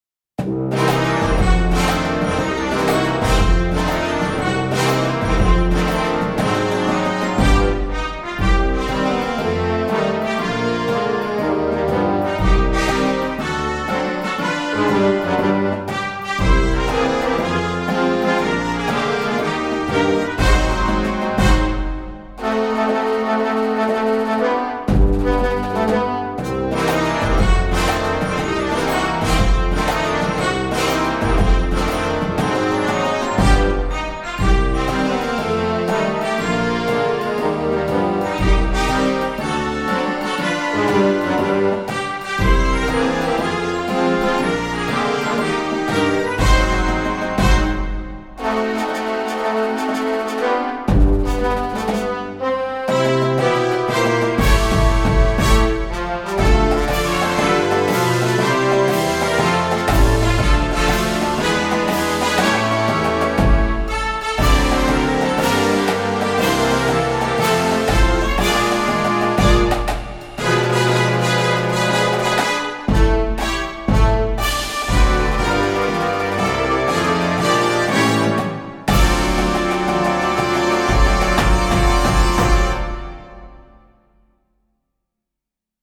Voicing: Marching Band